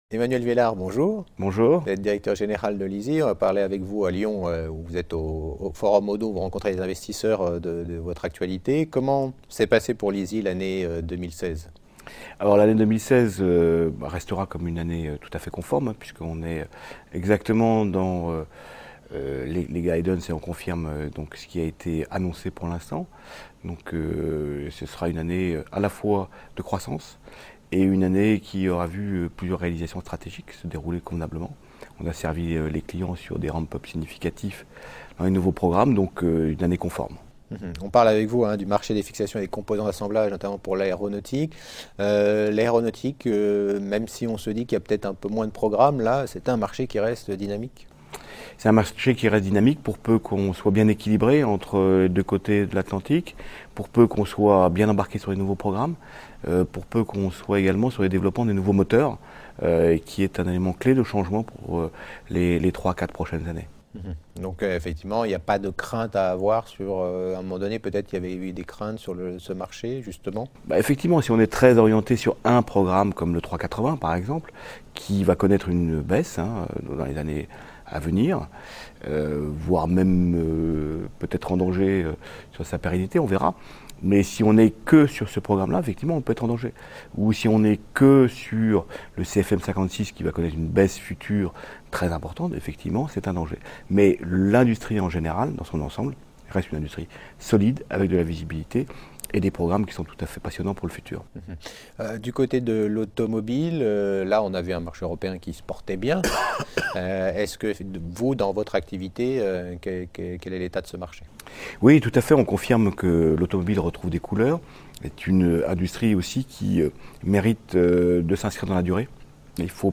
Rencontres avec les dirigeants au Oddo Forum de Lyon du 5 et 6 janvier 2017
La Web Tv à la rencontre des dirigeants d’entreprises cotées au Oddo Forum de Lyon du 5 et 6 janvier 2017.